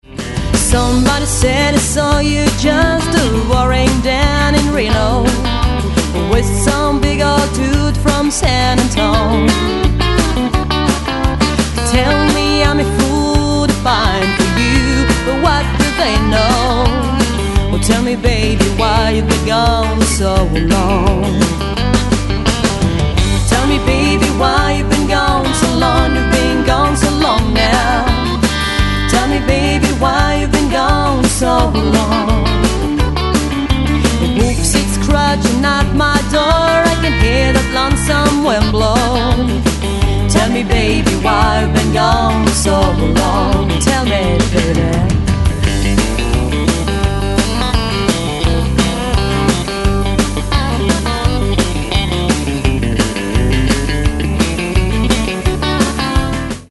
Chant, Harmonies
Basse, Programmation Batterie, Harmonies
Guitare Acoustique & Electrique, Programmation Batterie
Guitare Additionnelle